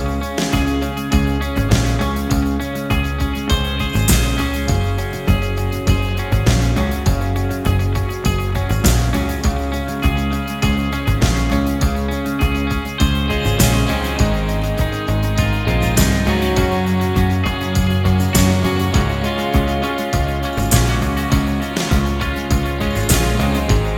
Minus Lead Guitar Indie / Alternative 4:31 Buy £1.50